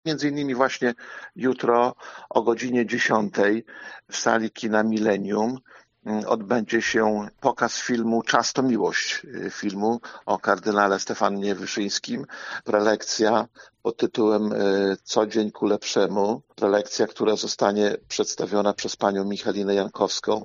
Starosta dodaje, że przygotowania dały owoce: